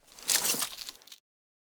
Weapons, Bow, Grab Arrow, Drag, Pull, Bag Rustle SND127810 S02.wav